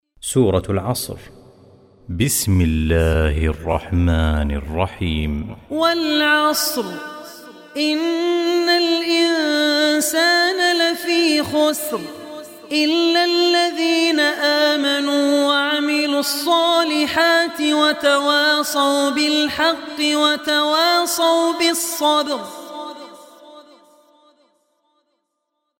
surah-asr.mp3